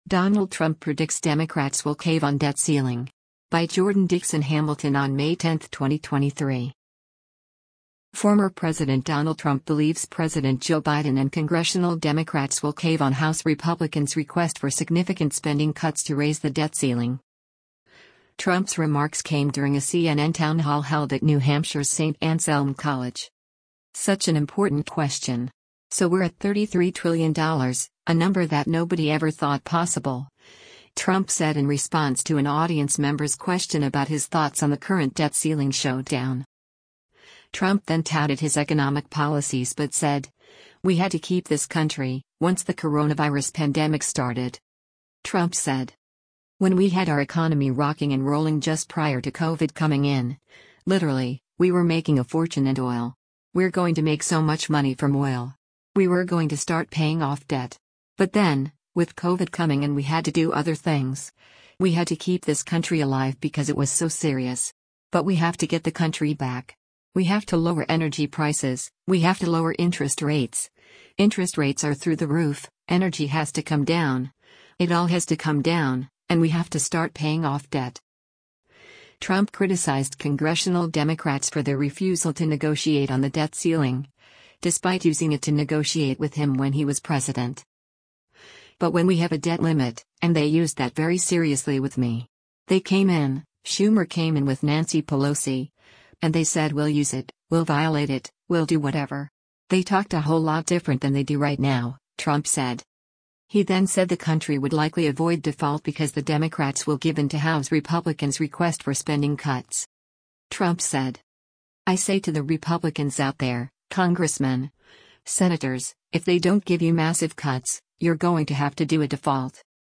Trump’s remarks came during a CNN Townhall held at New Hampshire’s Saint Anselm College.